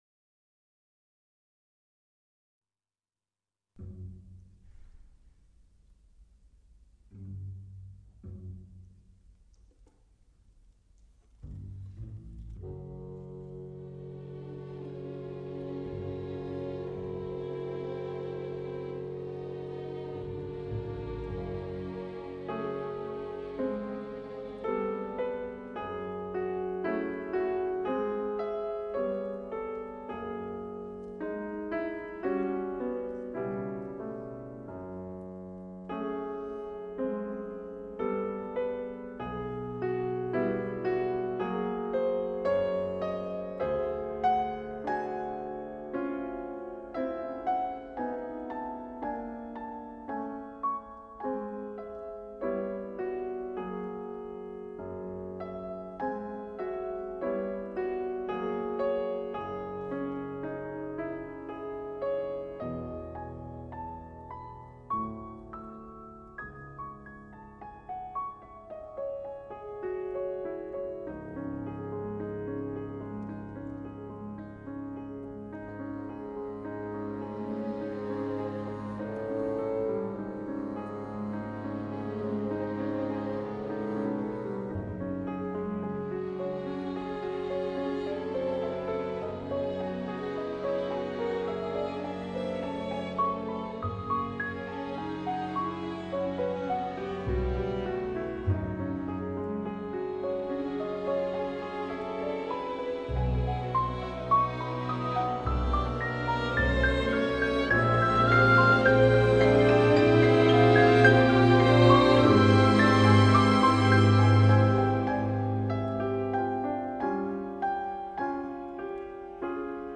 per pianoforte e piccola orchestra